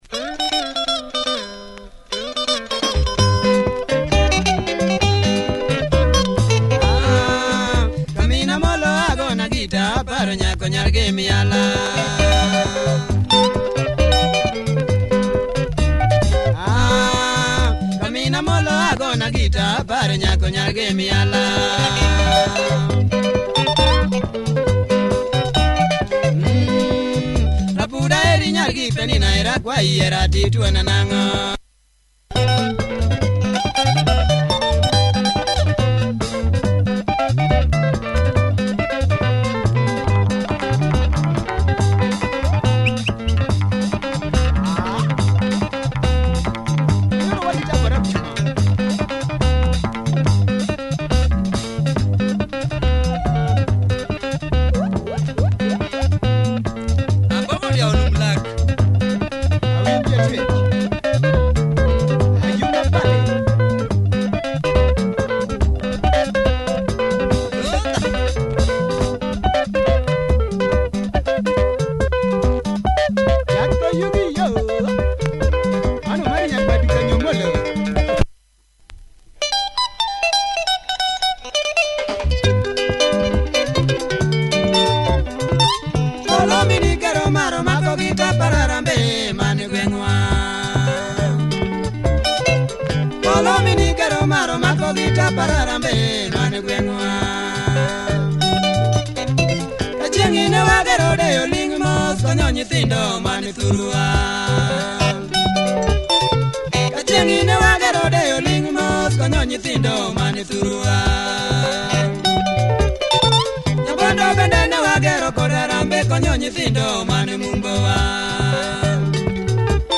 Great tight LUO benga with improvising guitar parts.